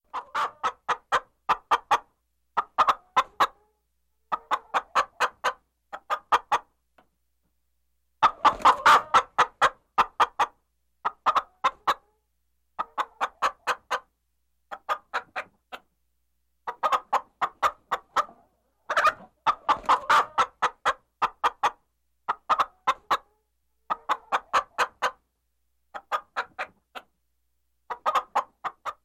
chicken-heck-sound